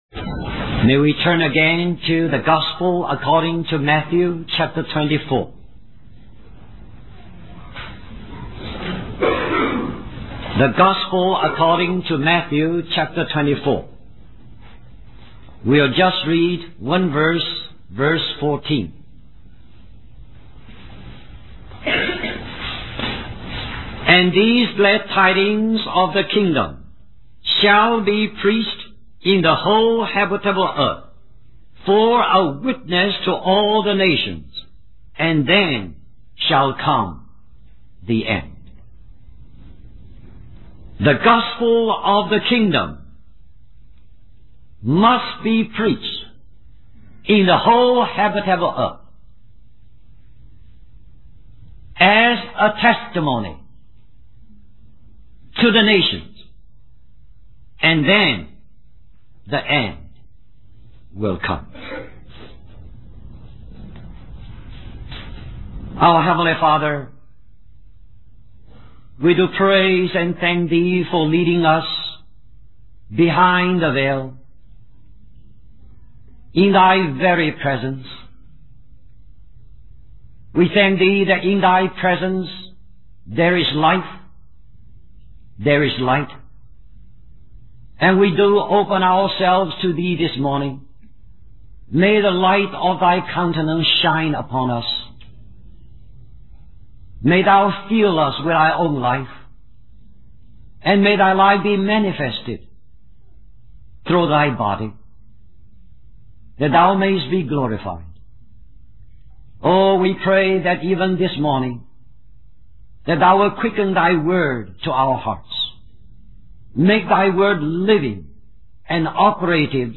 1979 Christian Family Conference Stream or download mp3 Summary In Matthew 24:14, it is stated that the Gospel of the kingdom must be preached to all nations before the end comes.